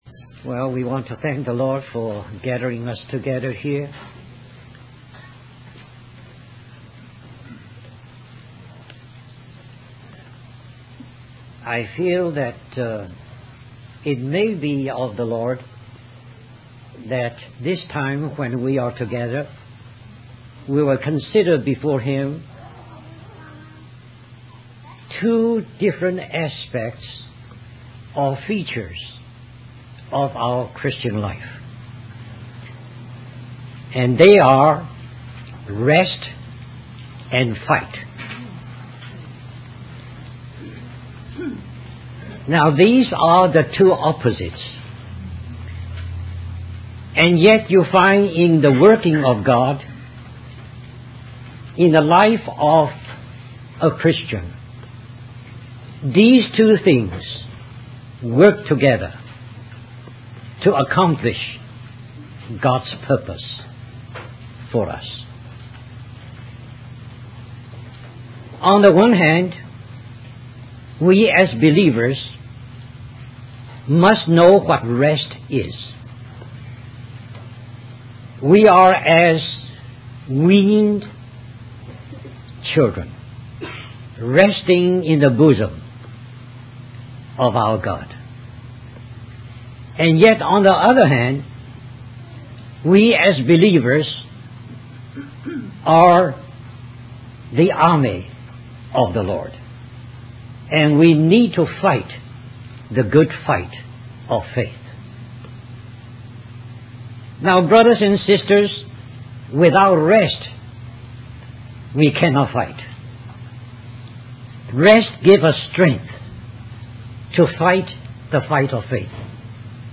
In this sermon, the preacher focuses on the words of Jesus in Matthew 11:28, where He invites all who are burdened and weary to come to Him for rest. The preacher emphasizes that Jesus is the only one who can truly give us rest because He took upon Himself our sins and paid the penalty for them on the cross.